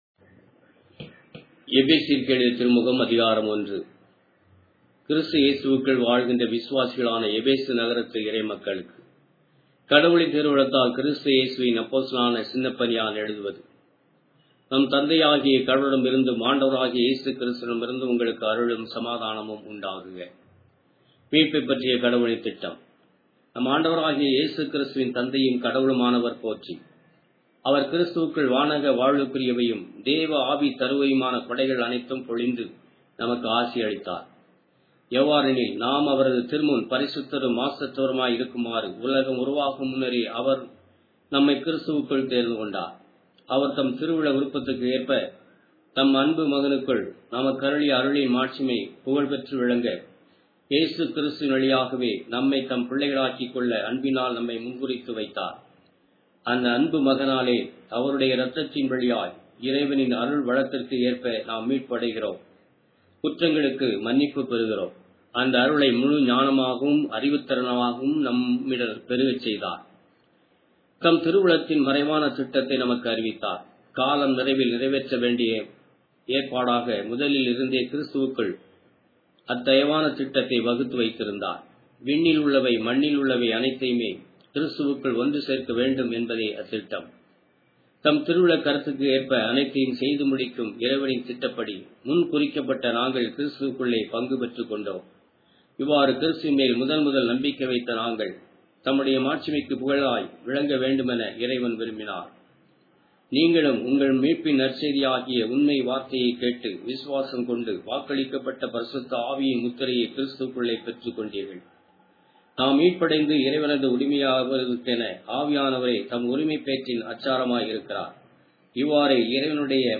Tamil Audio Bible - Ephesians 3 in Rcta bible version